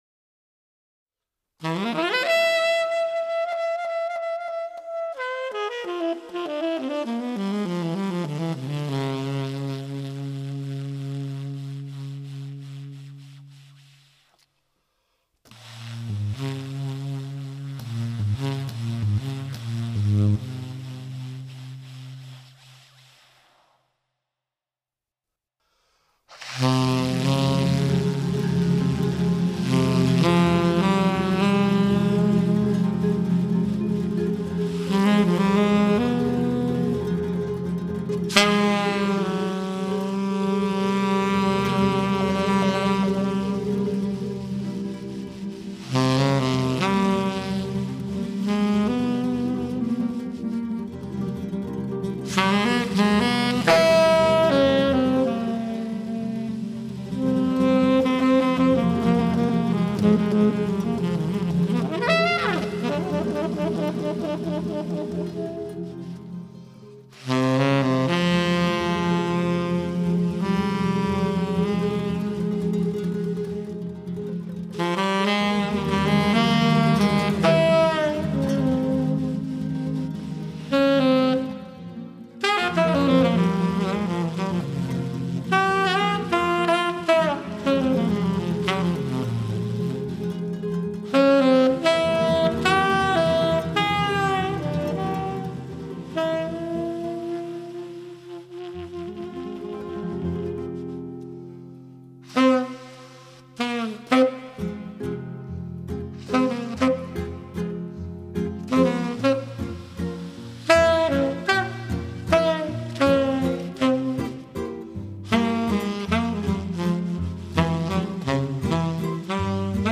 Hier einige Kostproben der music for a documentary film: